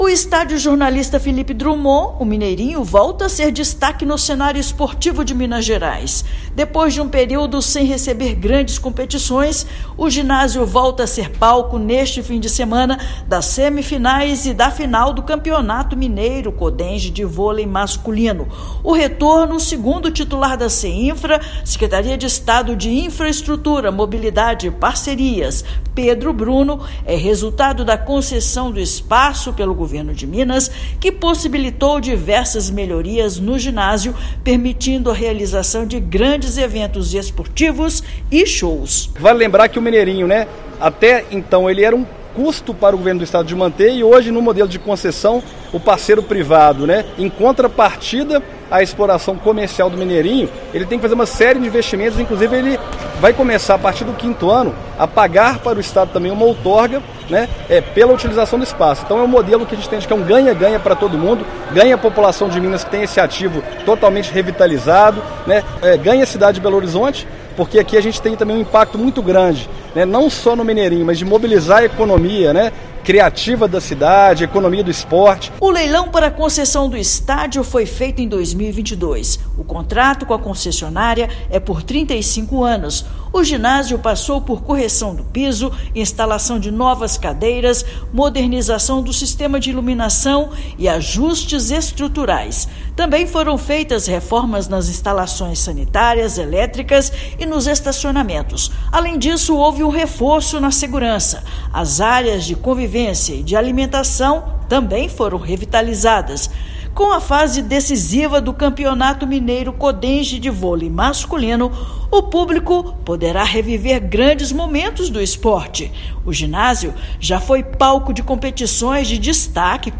Após concessão, ginásio sediará as semifinais e a final do Campeonato Mineiro Codemge de Vôlei Masculino, depois de cinco anos sem receber competições da modalidade. Ouça matéria de rádio.